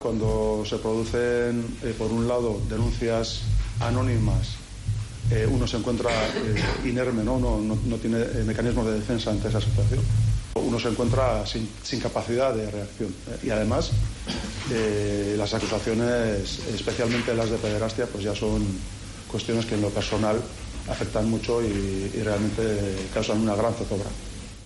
El Diputado General ha declarado hoy en los juzgados por las calumnias e injurias vertidas presuntamente en su contra por el ex presidente de la Real Sociedad